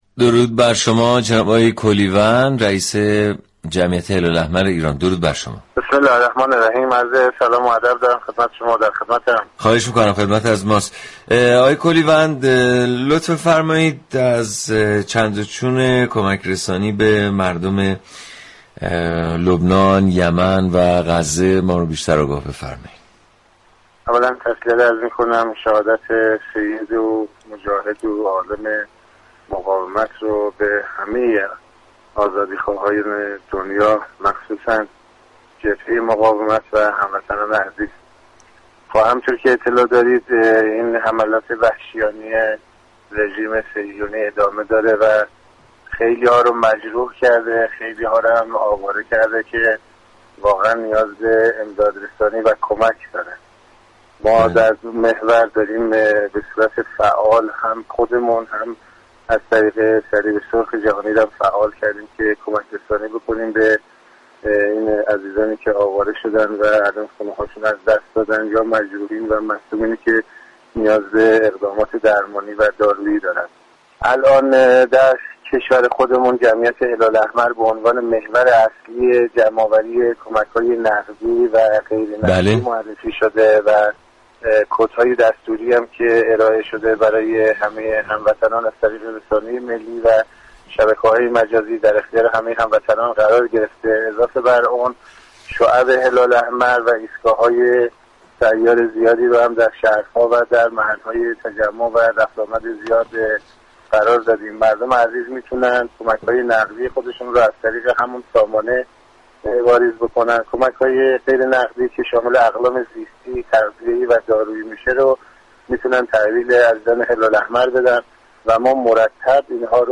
رادیو صبا در ویژه برنامه «طلوع نصر» در گفتگو با رئیس جمعیت هلال احمر درباره جزئیات امداد رسانی به مردم لبنان توضیح داد.
این برنامه در گفتگو با پیرحسین كولیوند رئیس جمعیت هلال احمر ایران درباره جزئیات كمك رسانی به مردم لبنان توضیحاتیبه مخاطبان ارائه كرد.